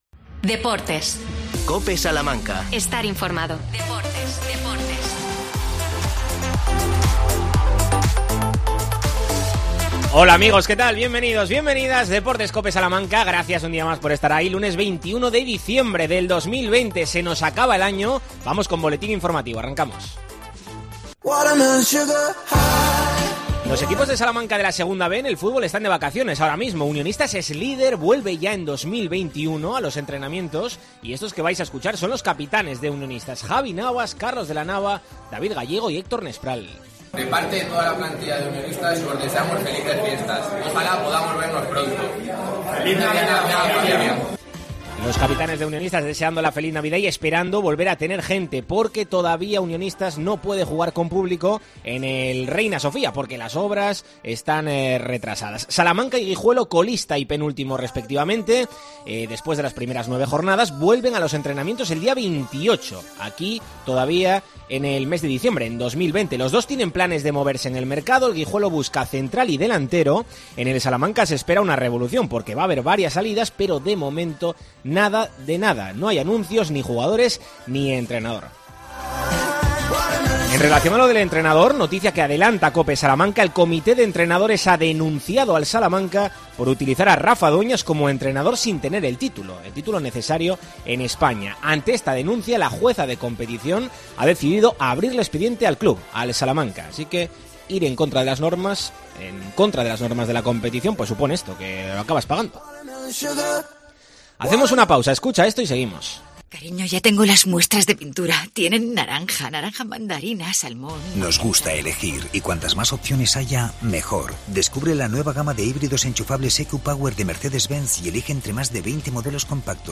AUDIO: Boletín informativo. La Segunda B, de vacaciones. El Comité de Entrenadores denuncia al Salamanca. Resultados del fin de semana.